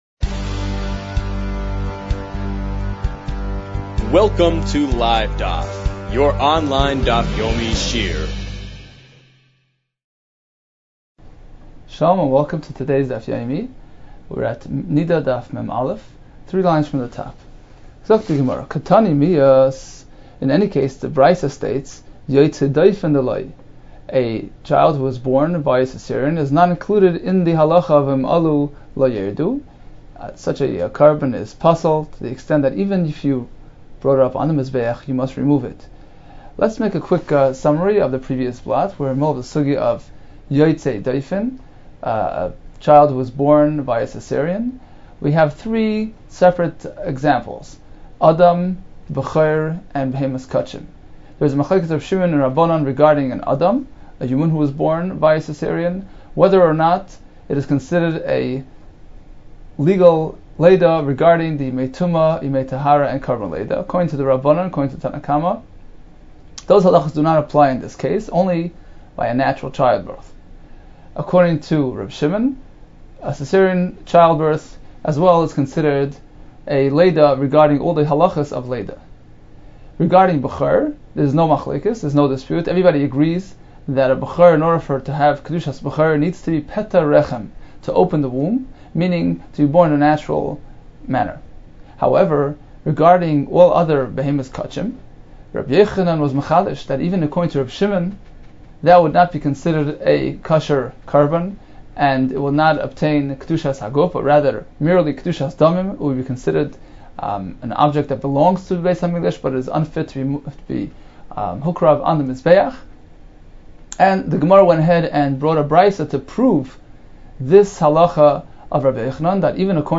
Niddah 41 - נדה מא | Daf Yomi Online Shiur | Livedaf